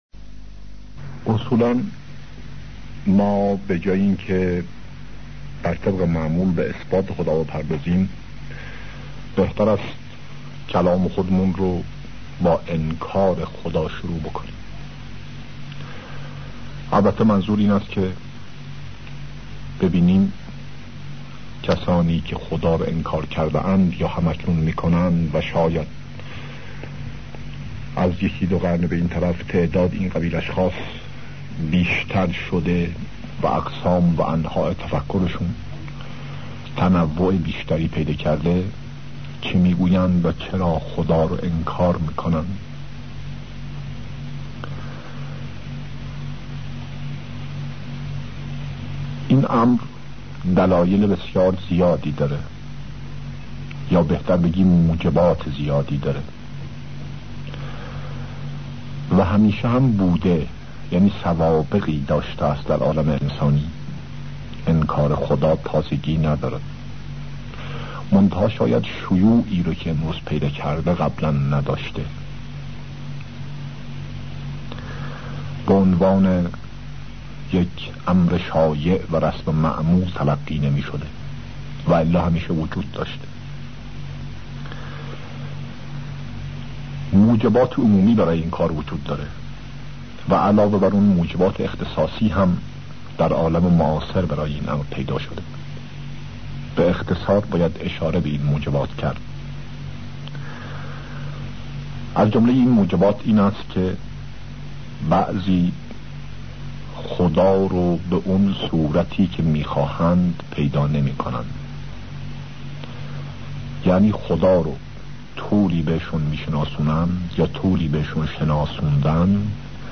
سخنرانی هایی پیرامون عقاید بهائی